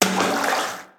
WaterSplash_In_Short2.wav